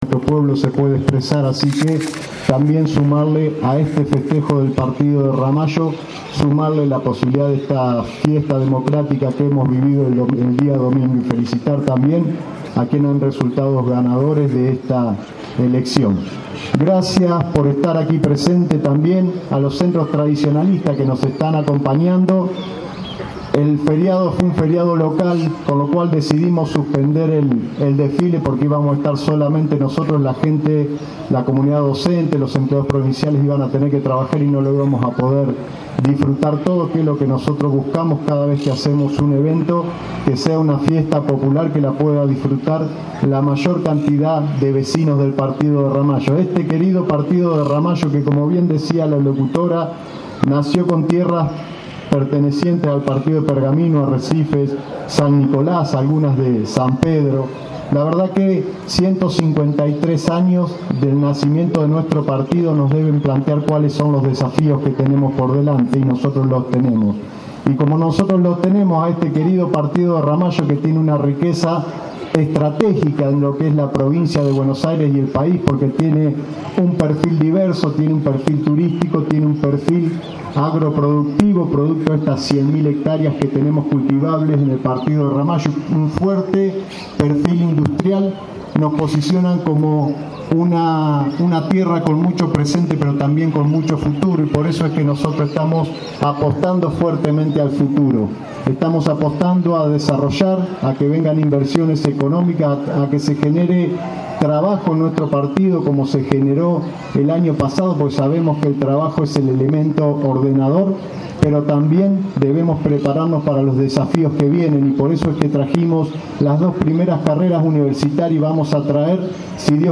153º ANIVERSARIO DE RAMALLO. ACTO CENTRAL EN LA PLAZA PRINCIPAL JOSÉ MARÍA BUSTOS.
Audio: Único orador el int. de Ramallo Mauro Poletti
Int.-Mauro-Poletti.mp3